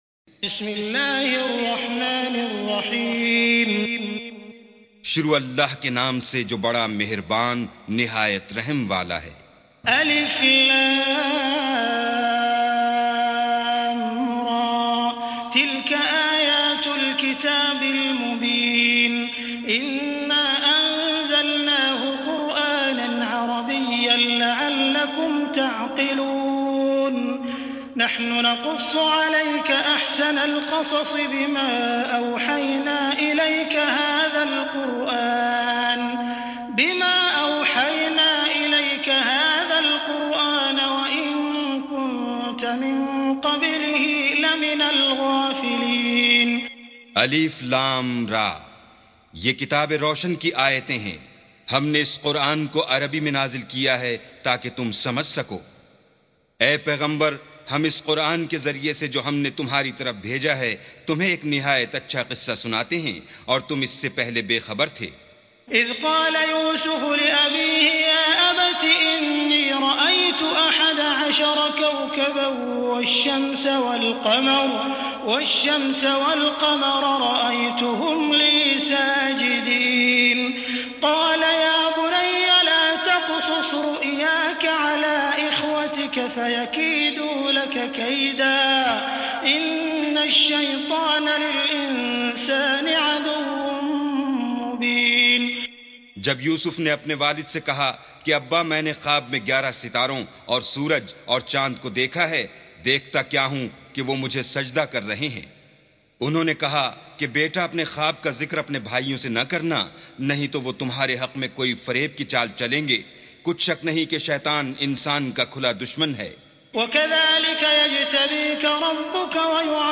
سُورَةُ يُوسُفَ بصوت الشيخ السديس والشريم مترجم إلى الاردو